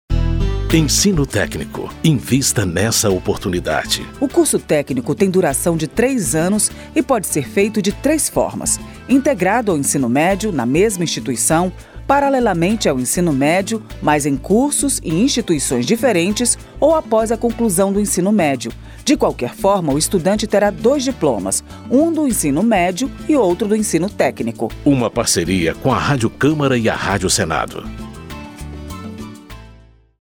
Por isso, a Rádio Câmara e a Rádio Senado criaram cinco spots com informações sobre educação técnica e profissional, mostrando o valor das carreiras técnicas e incentivando o investimento nesse setor.